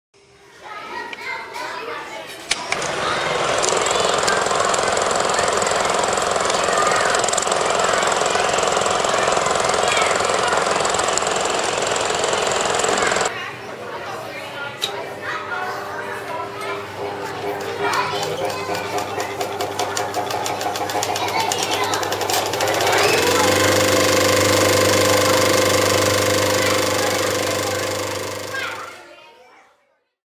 super8.mp3